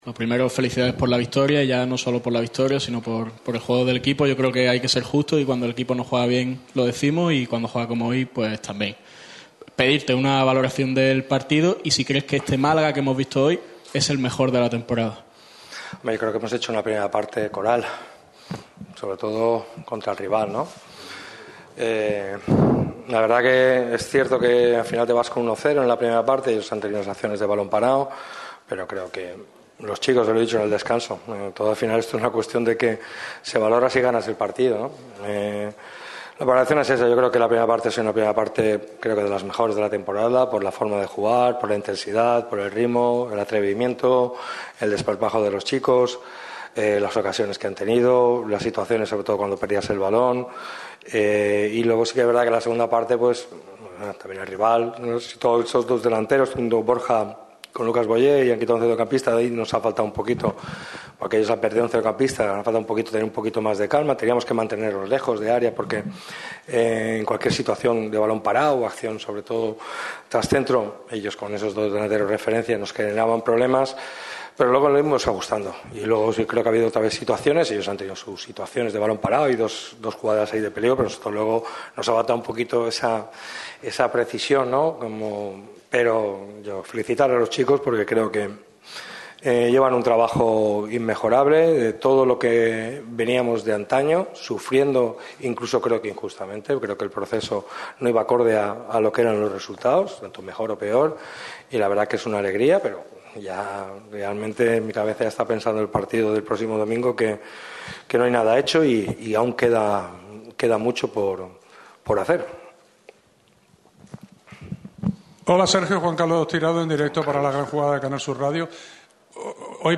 El entrenador del Málaga CF, Sergio Pellicer, ha atendido a los medios de comunicación en sala de prensa después de vencer al Granada en La Rosaleda. El de Nules ha valorado el triunfo, ha repasado los nombres propios del partido y aún no da por certificada la permanencia a pesar de los nueve puntos de ventaja momentáneos sobre el Eldense.